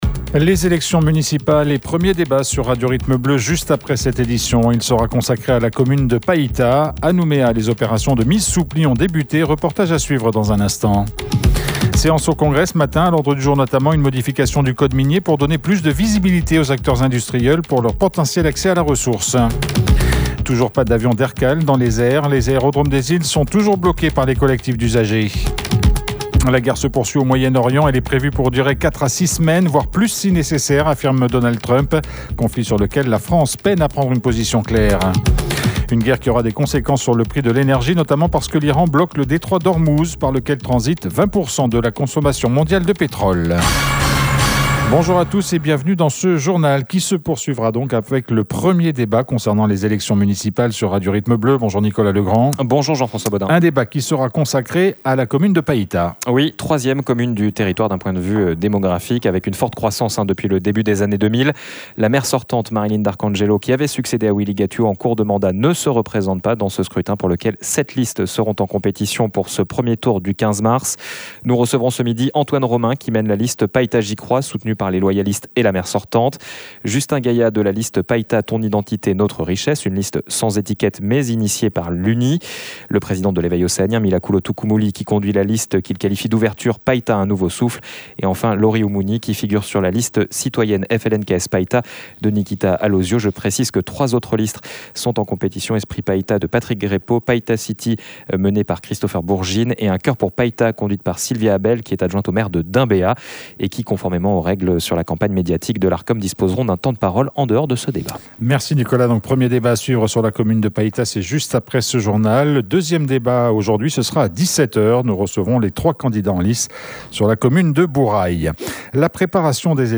A propos de la campagne municipale, reportage suivre sur la mise sous pli des programmes des différents candidats à Nouméa.